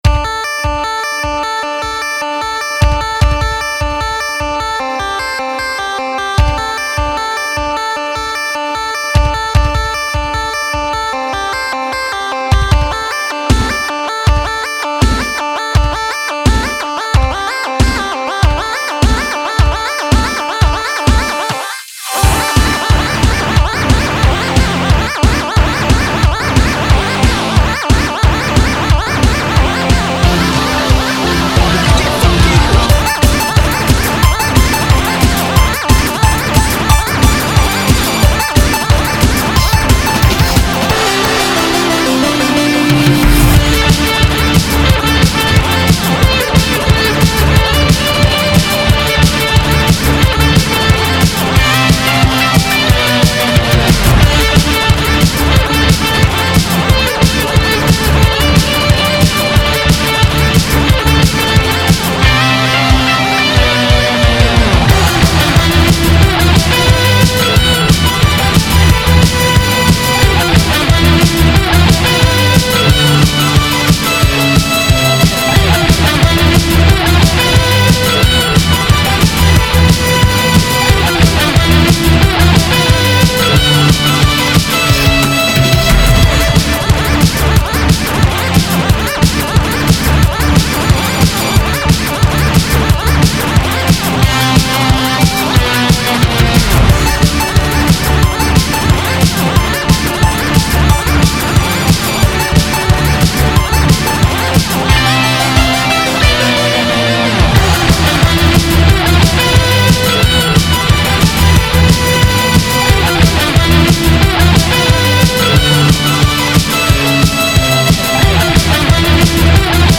BPM151-180
Audio QualityPerfect (High Quality)
VGM song for StepMania, ITGmania, Project Outfox
Full Length Song (not arcade length cut)